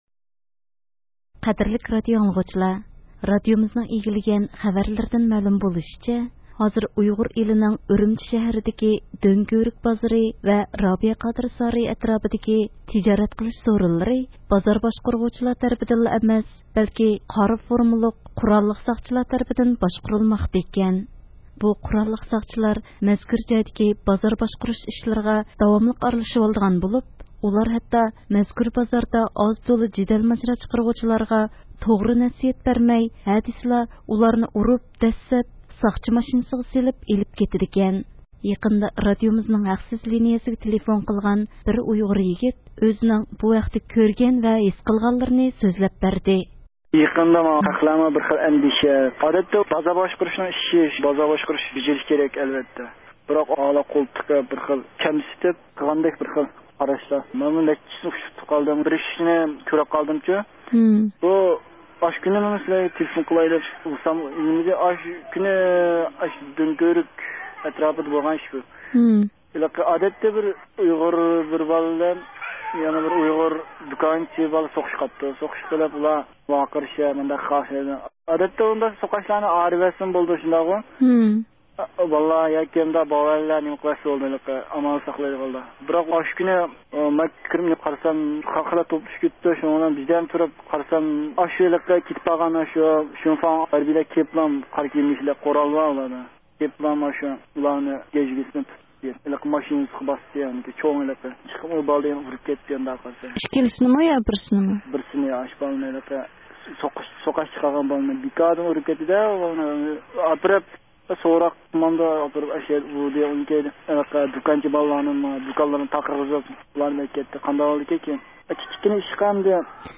يېقىندا رادىئومىزنىڭ ھەقسىز لېنىيىسىگە تېلېفون قىلغان بىر ئۇيغۇر يىگىت ئۆزىنىڭ بۇ ھەقتە كۆرگەن ۋە ھېس قىلغانلىرىنى سۆزلەپ بەردى.